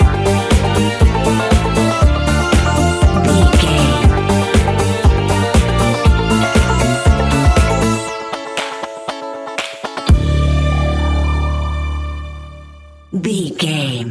Ionian/Major
D
house
electro dance
synths
techno
trance
instrumentals